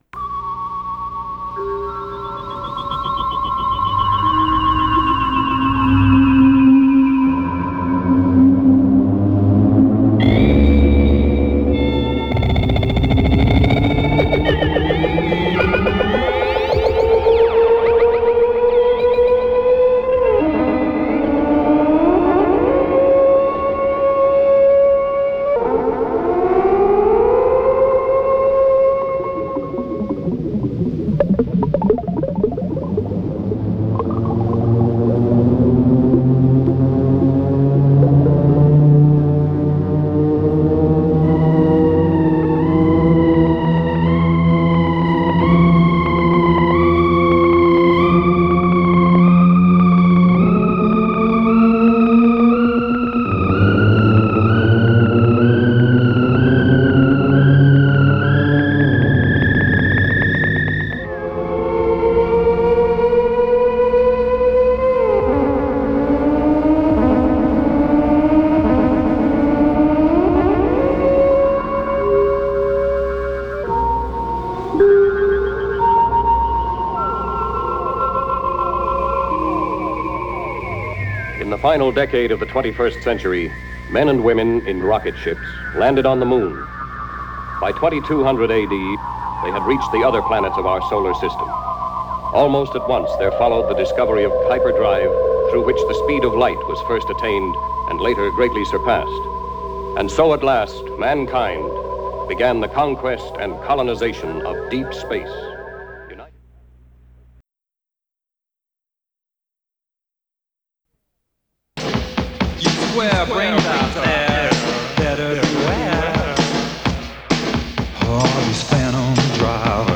電子音楽